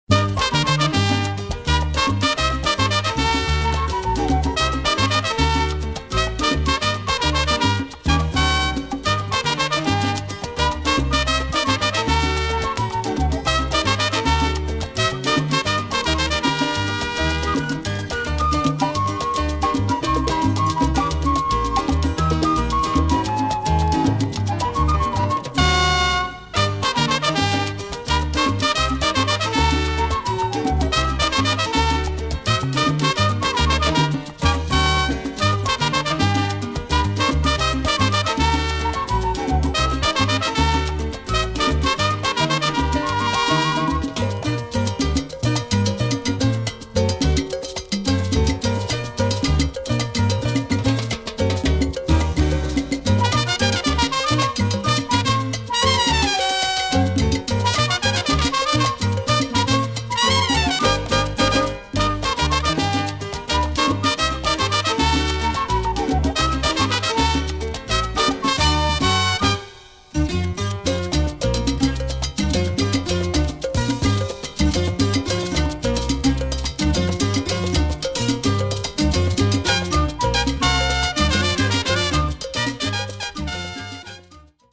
Latin / salsa